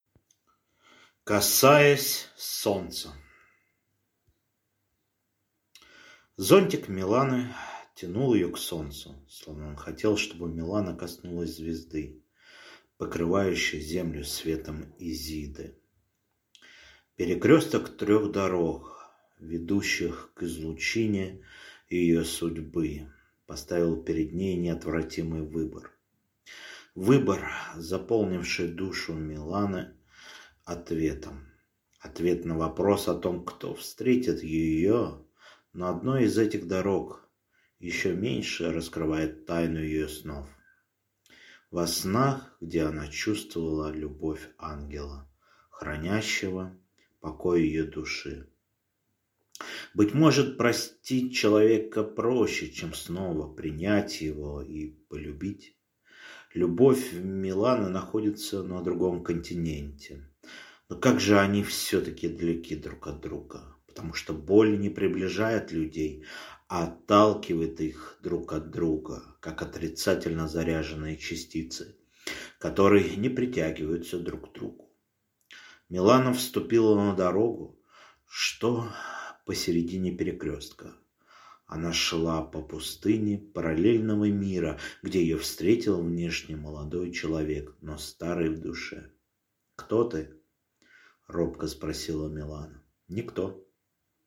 Аудиокнига Касаясь Солнца | Библиотека аудиокниг